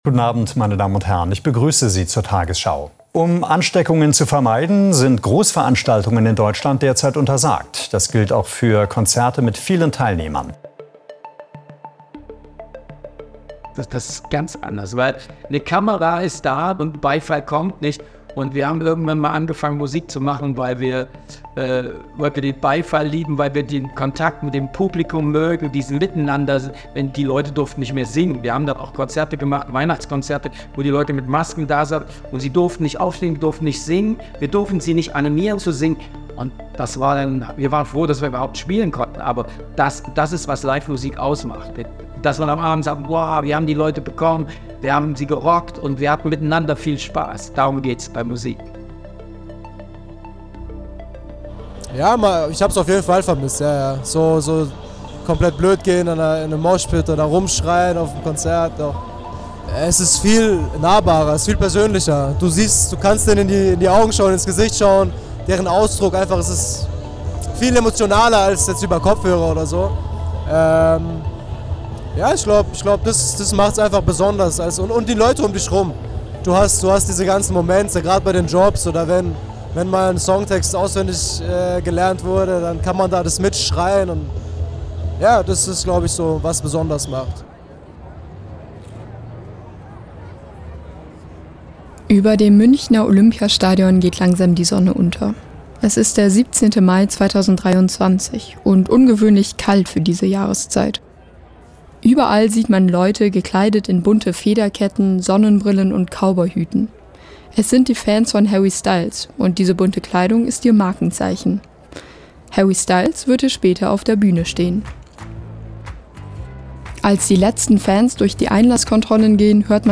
Welchen Herausforderungen begegnet die Musikindustrie im Moment? In diesem Feature sprechen Veranstaltende, Manager:innen und Musiker:innen über ihren Alltag in einer Zeit, die von Inflation und Pandemie geprägt ist.